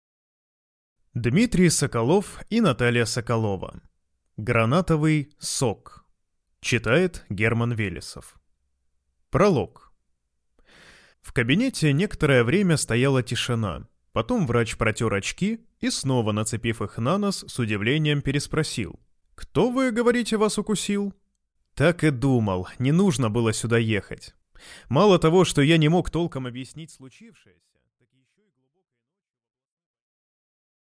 Аудиокнига Гранатовый сок | Библиотека аудиокниг